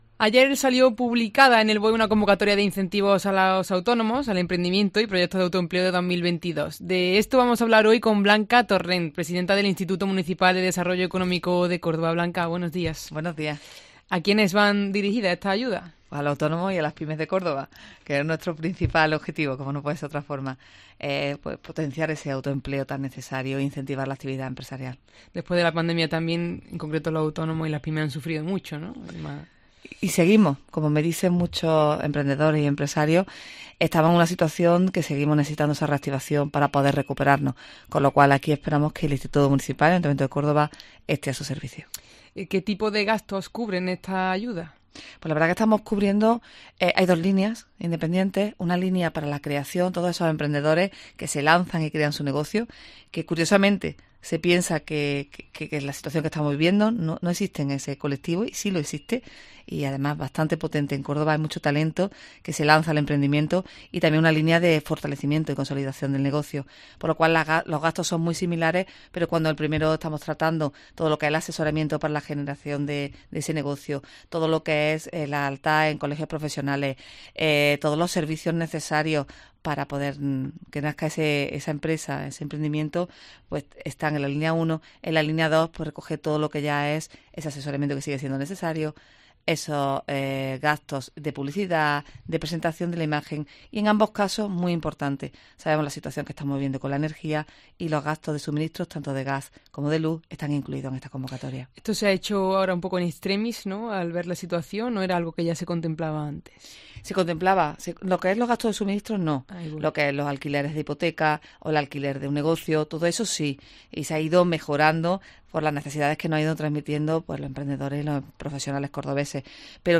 Blanca Torrent, presidenta del IMDEEC, presenta las nuevas ayudas a autónomos y PYMES para el año 2022